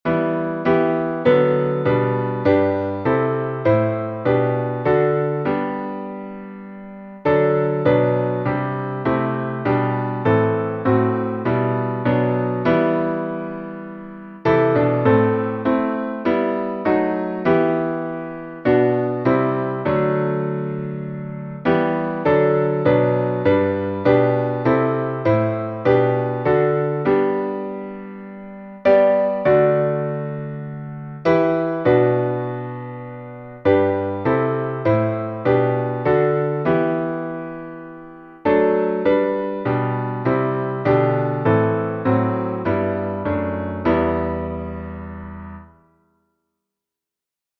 Melodia francesa, século XV
salmo_85B_instrumental.mp3